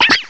cry_not_inkay.aif